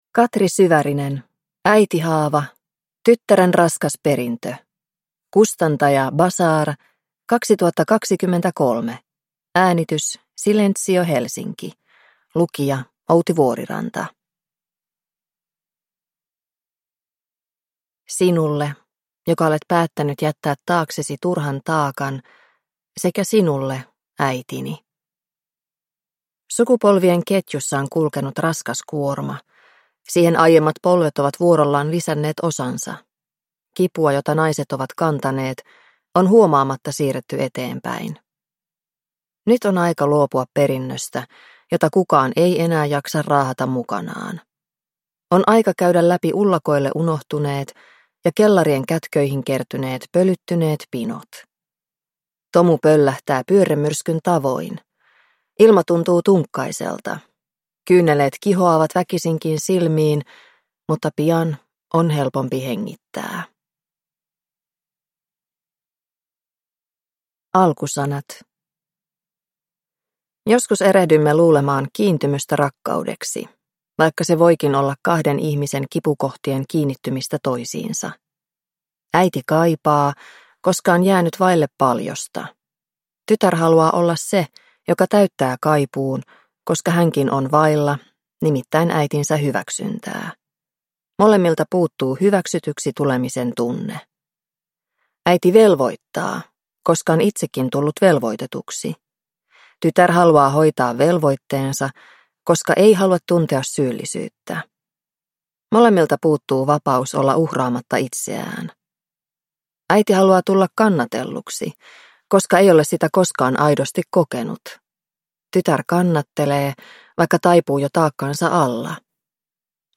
Äitihaava – tyttären raskas perintö – Ljudbok – Laddas ner